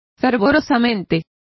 Complete with pronunciation of the translation of fervently.